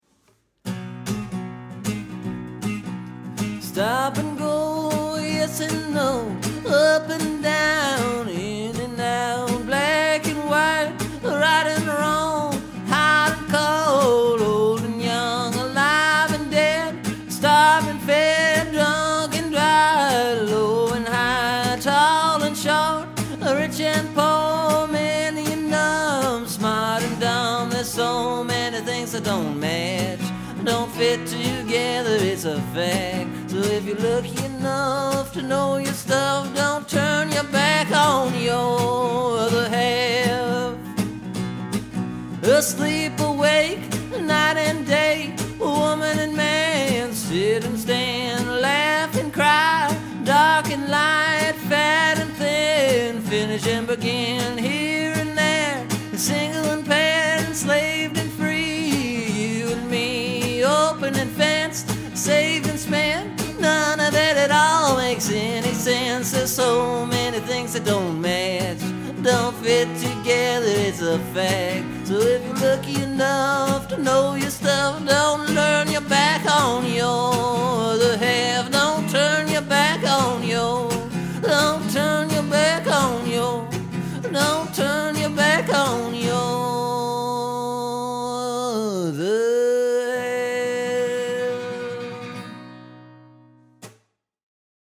For this one I added a chorus part and I changed the singing from a talkin’ blues to a more straight up blues tune. I think either way works well, but the chorus definitely adds something.